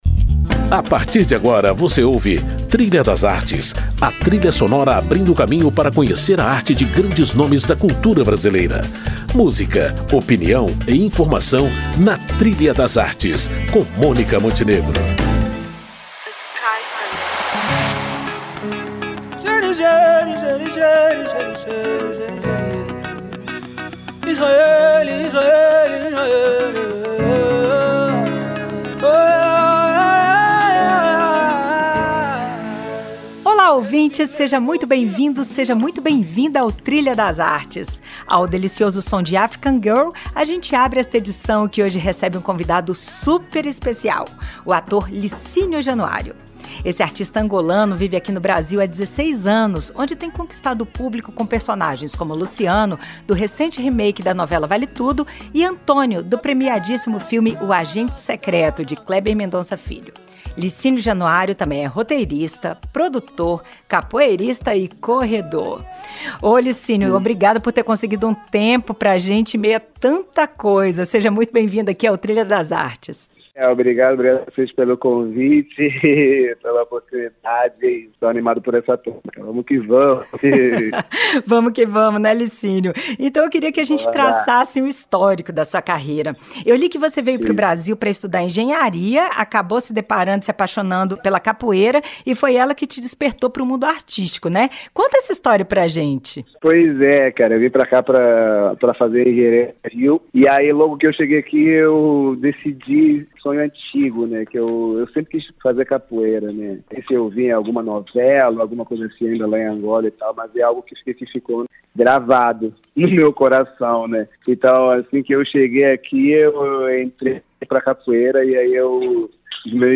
Numa conversa embalada por músicas de Angola, Nigéria e Bahia, ele fala sobre sua jornada da engenharia à capoeira, que acabou sendo a porta de entrada para os palcos, a TV e as telas de cinema.
Representatividade, identidade e quebra de estereótipos também fizeram parte desta entrevista imperdível!